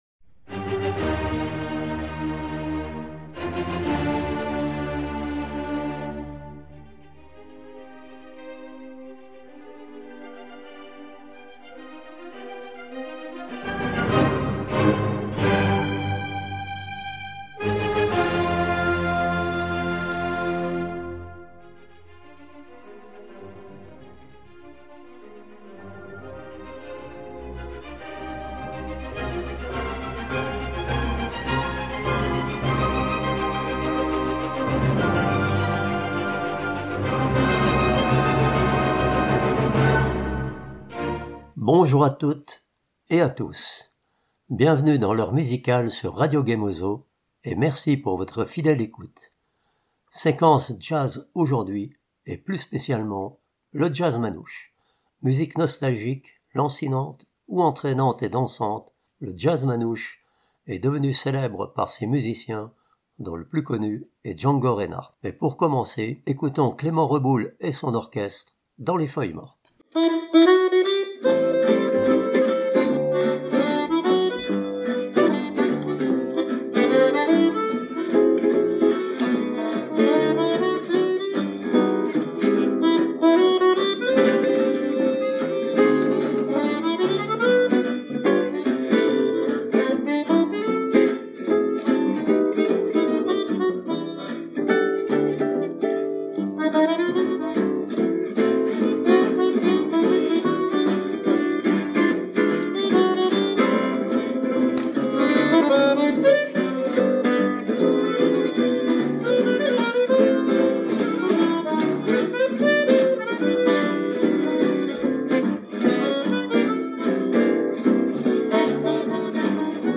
Jazz manouche
une heure de jazz manouche!